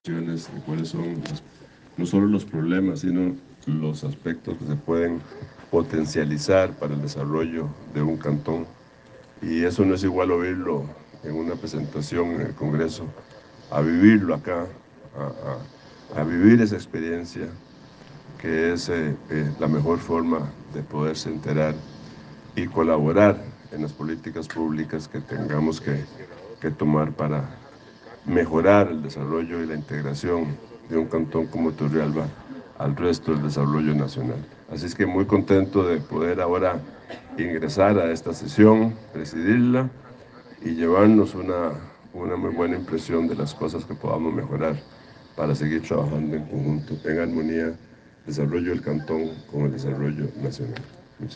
Declaración Rodrigo Arias Sánchez
Declaracion-Rodrigo-Arias.ogg